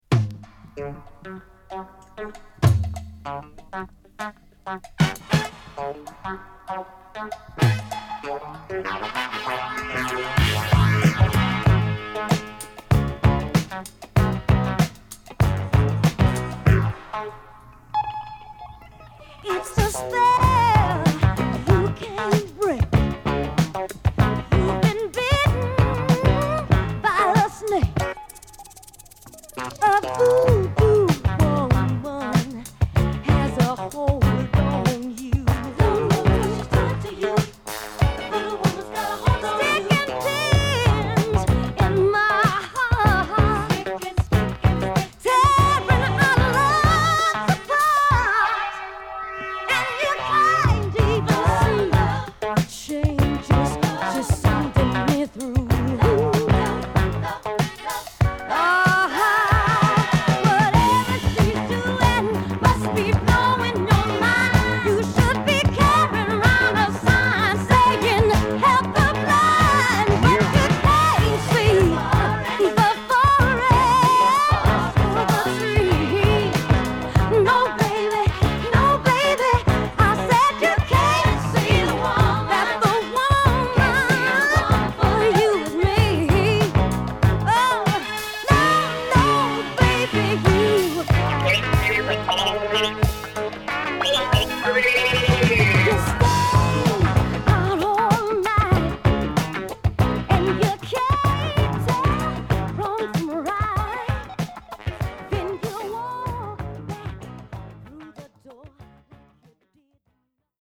骨太なファンキーサウンドでパワフルに歌うファンキーソウル！